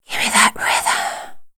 WHISPER 01.wav